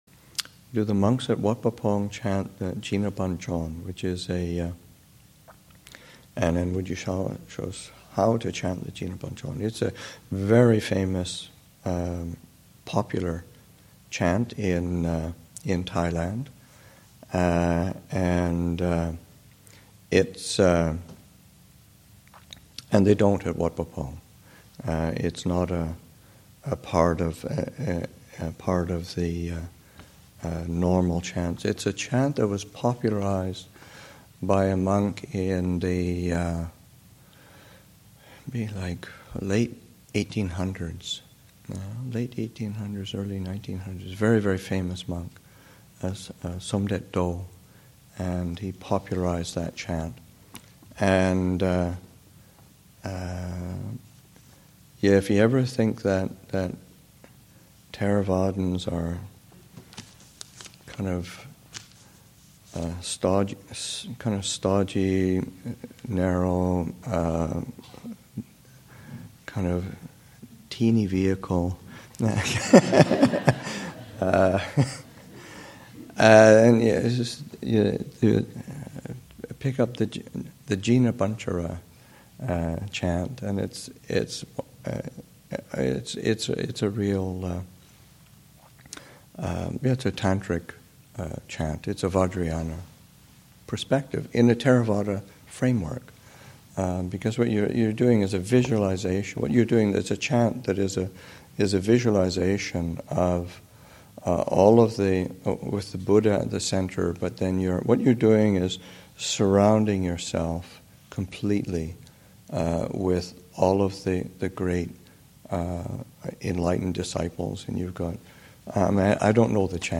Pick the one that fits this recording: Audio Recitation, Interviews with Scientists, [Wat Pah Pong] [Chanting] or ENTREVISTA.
[Wat Pah Pong] [Chanting]